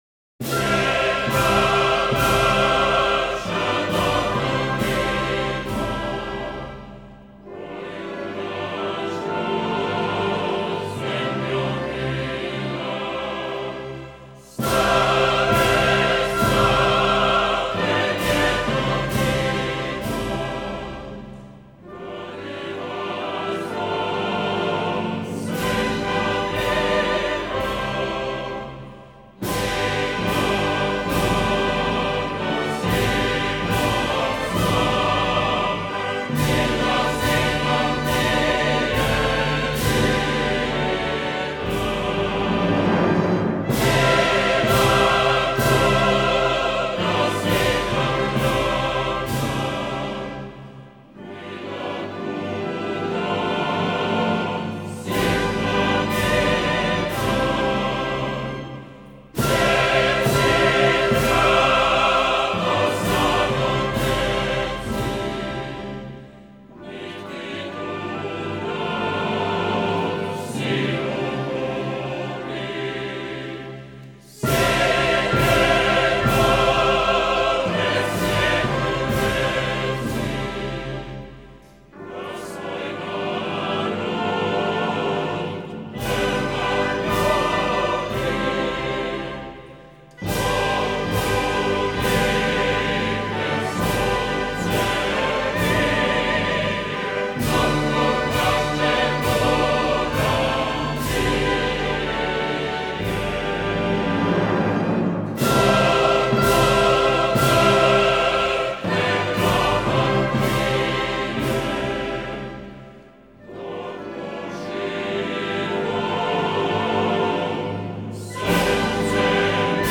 Christmas Show Songs – 2017